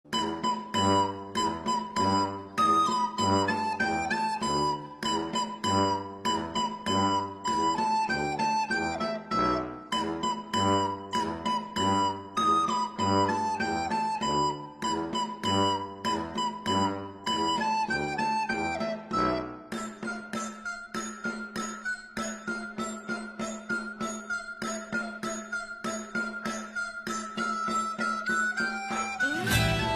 Рингтон из главной темы сериала